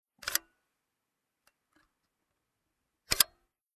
Pick-up lever
0156_Aufnahmehebel.mp3